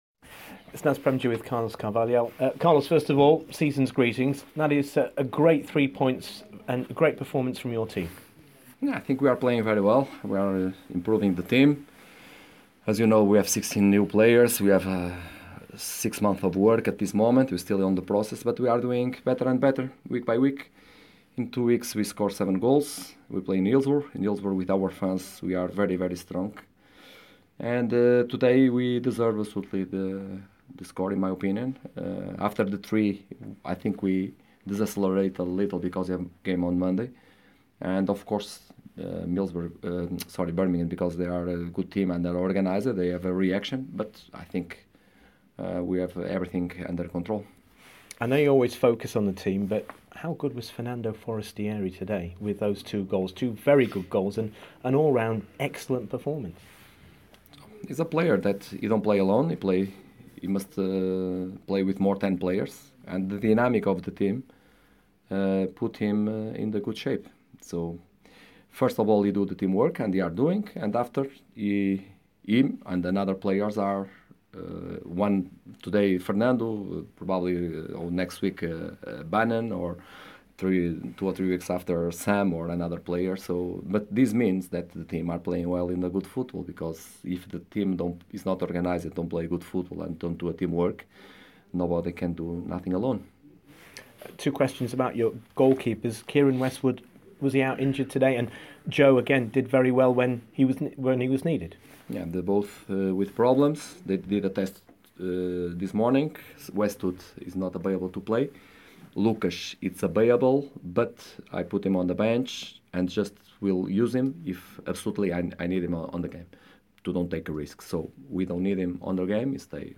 spoke to the Owls manager after the game.